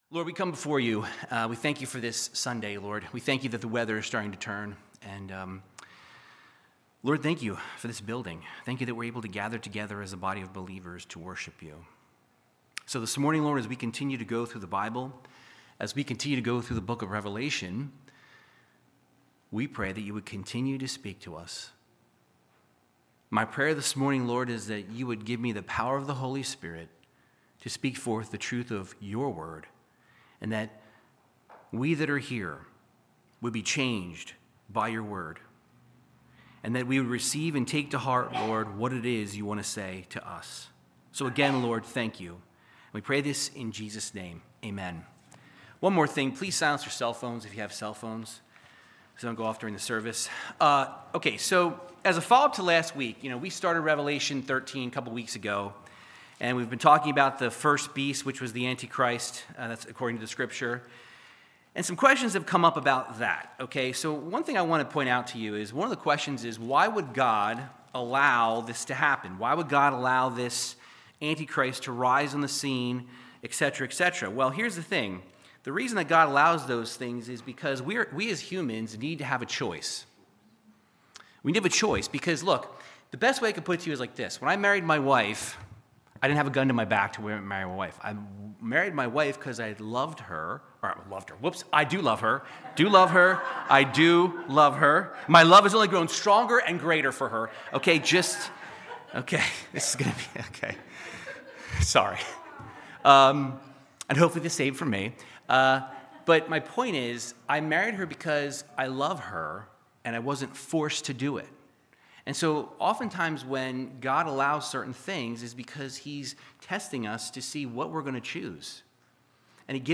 Verse by verse Bible teaching through the book of Revelation chapter 13